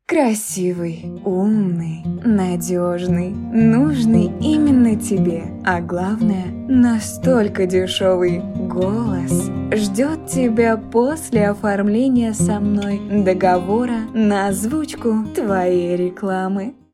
Жен, Рекламный ролик/Молодой